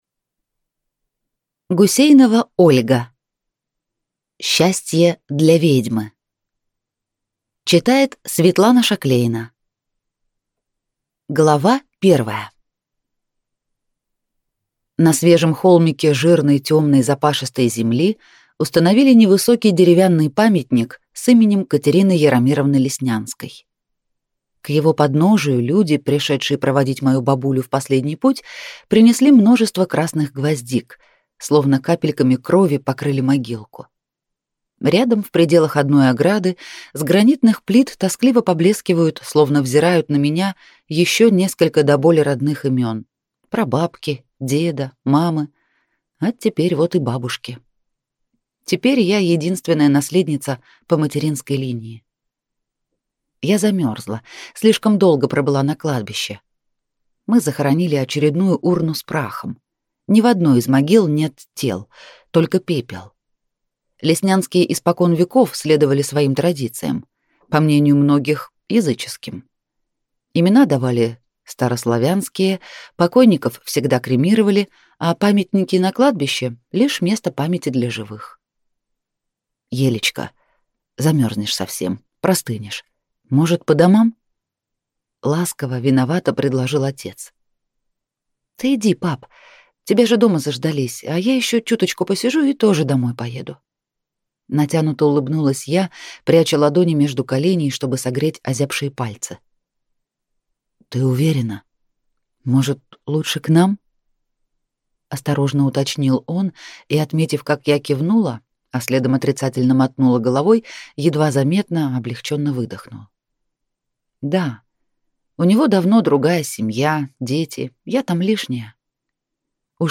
Аудиокнига Счастье для ведьмы | Библиотека аудиокниг